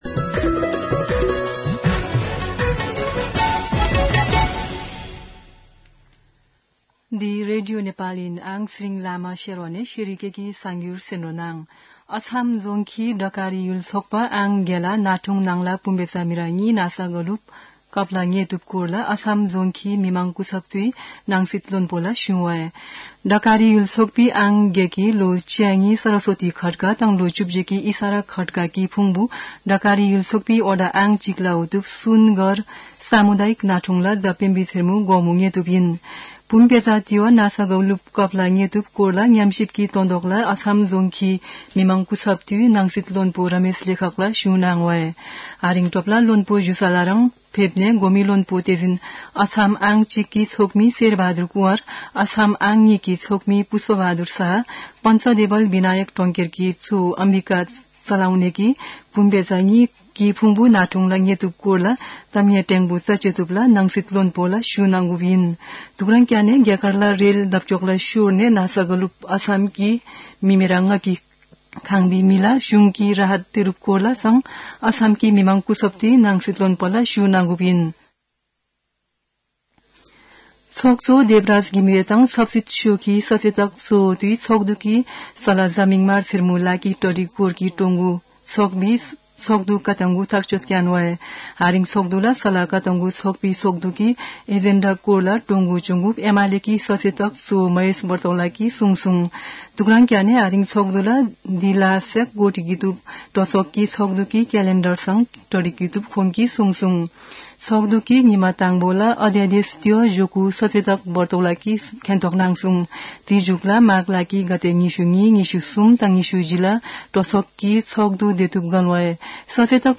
शेर्पा भाषाको समाचार : १५ माघ , २०८१
Sherpa-News-8.mp3